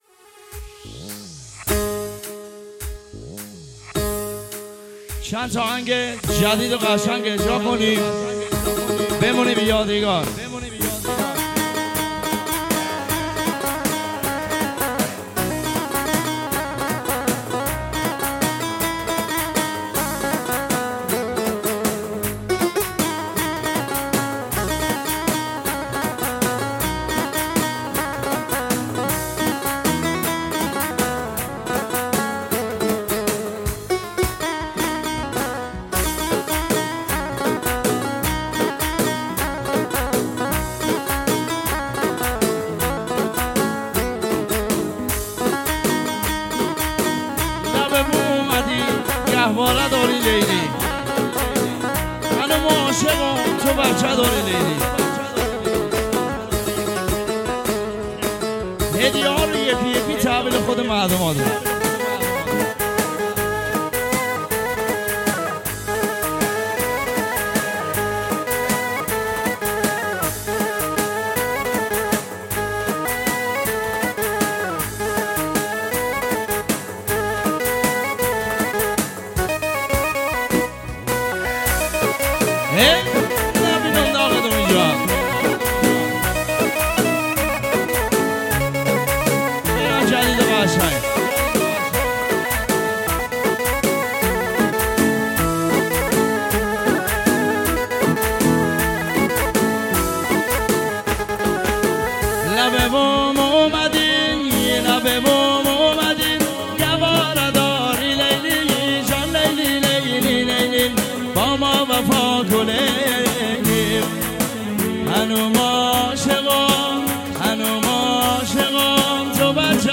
ترانه بسیار احساسی و دلنشین